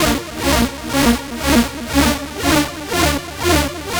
TSNRG2 Lead 003.wav